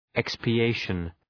expiation.mp3